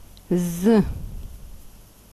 зы zoo